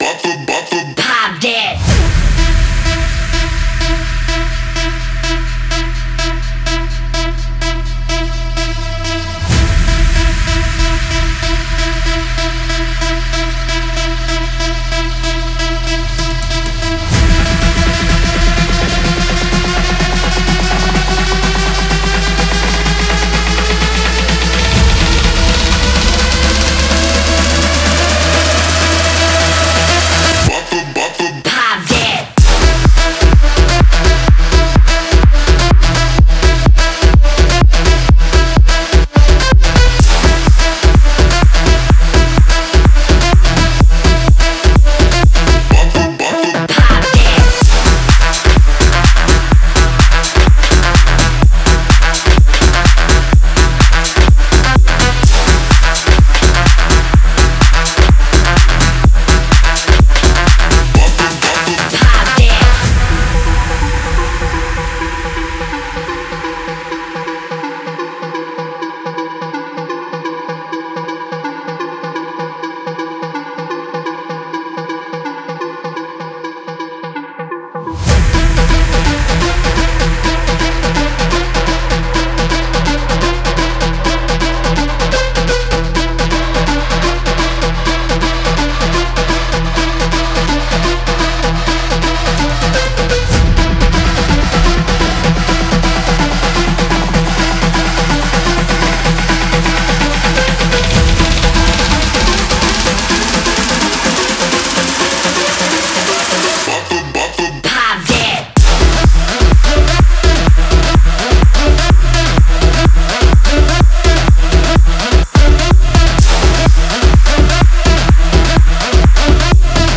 выполненная в жанре EDM